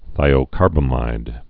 (thīō-kärbə-mīd)